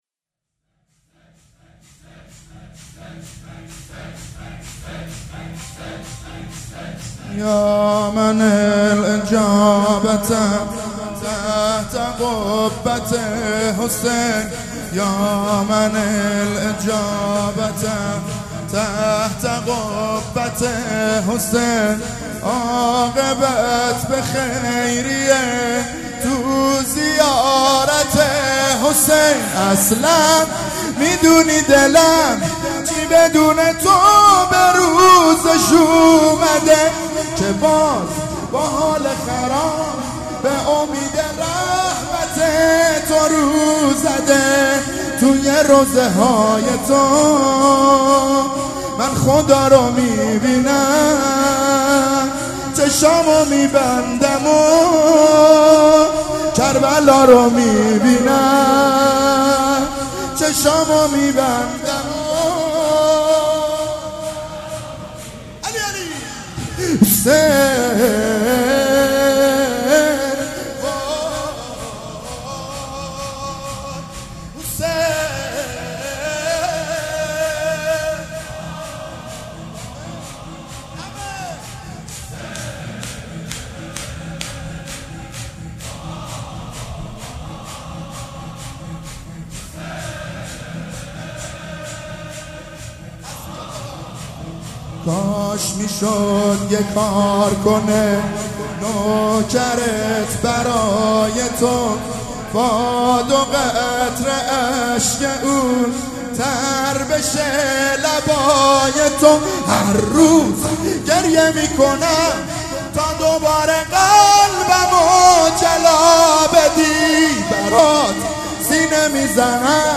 مـراسـم سیاه پوشان
شور
مداح
شور اول.mp3